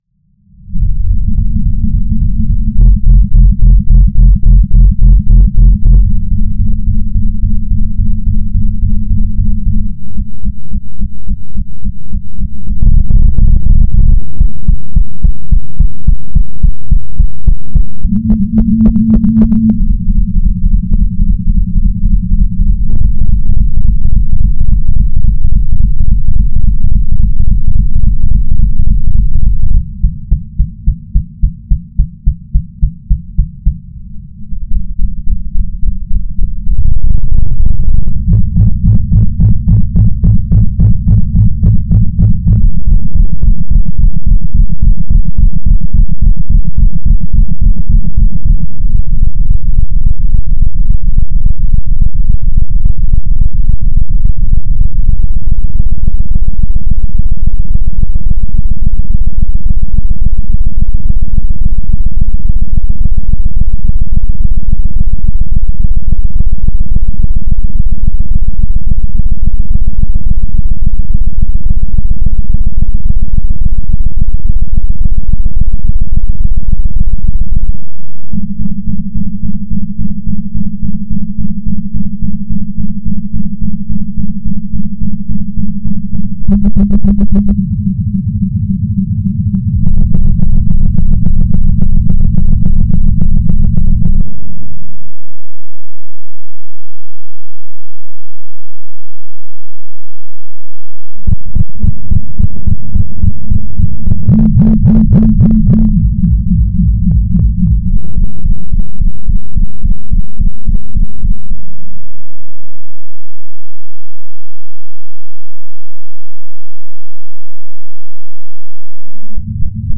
I have slowed the audio from the numbered series video “♐337321-aVuE7bgfPuI” and have been granted LOCK-esque wubbs:
OUS_SLOWED0.013.mp3